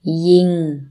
– ying